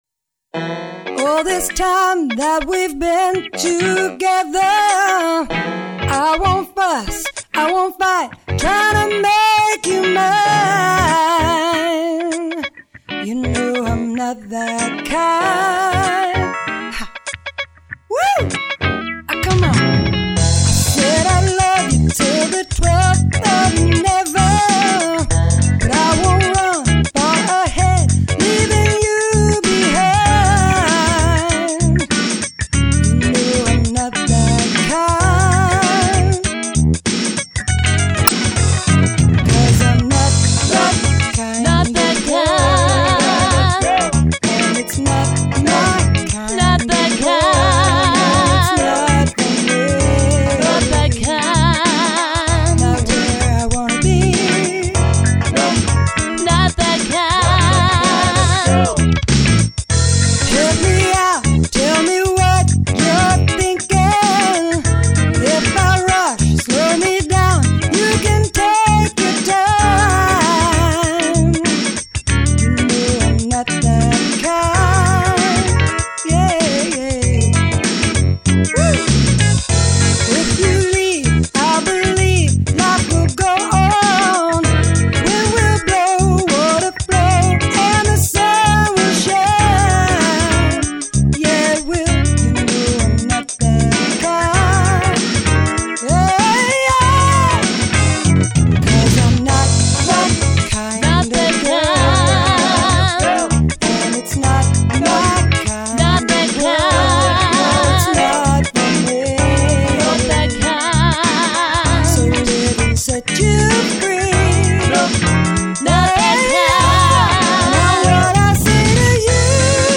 Pittsburgh's premiere dance music band
vocals